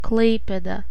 Klaipėda (/ˈklpɛdə/ CLAY-ped-ə; Lithuanian: [ˈklˠɐɪ̯ˑpʲeːdˠɐ]
Lt-Klaipeda.oga.mp3